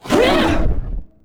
combat / creatures / ryuchi / she / attack2.wav
attack2.wav